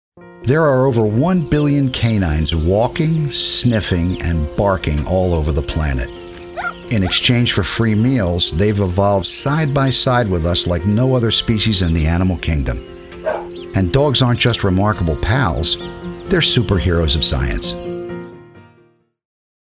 Documentary Professional Voice Over Talent | VoicesNow Voiceover Actors
Voice Over Talent Catalog